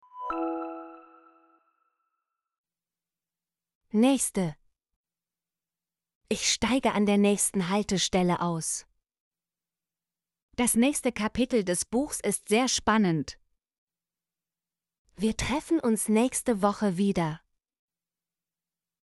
nächste - Example Sentences & Pronunciation, German Frequency List